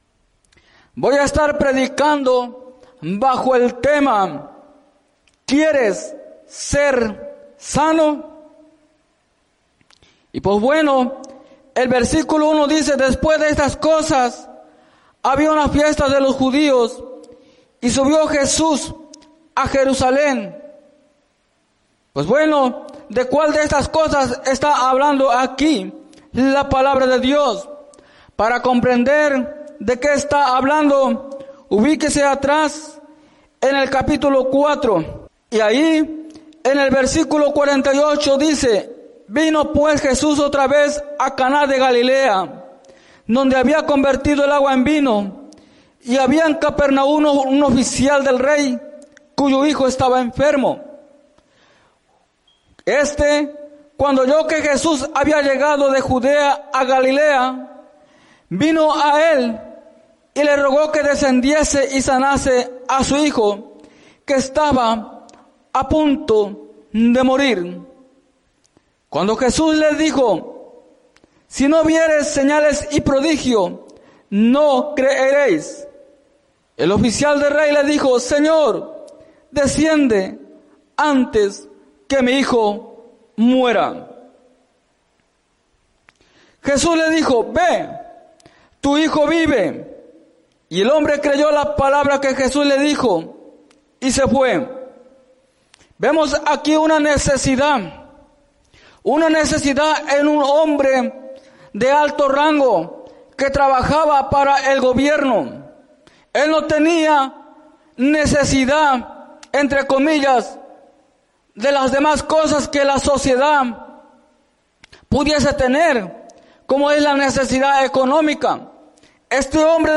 en la Iglesia Misión Evangélica en Norristown, PA